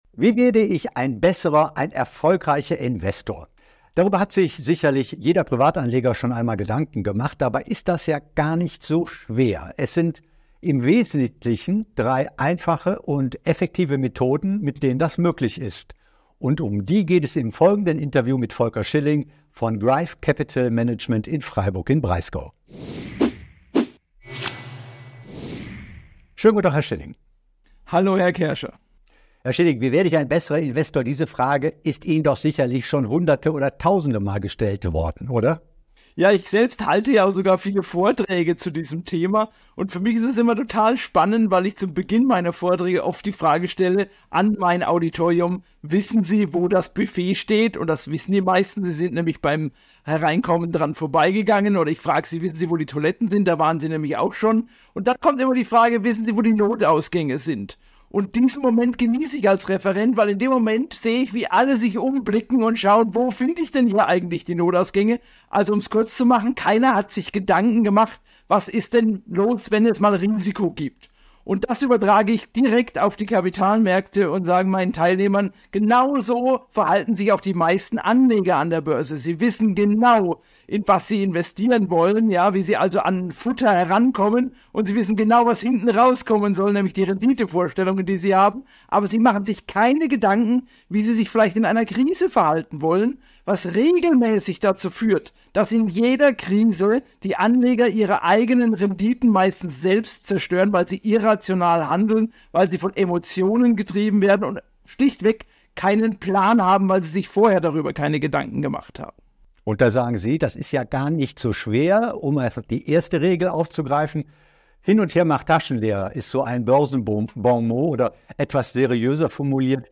Hören Sie sich das Interview an, um zu erfahren, wie Sie Ihr Investmentverhalten optimieren und Ihre Rendite steigern können.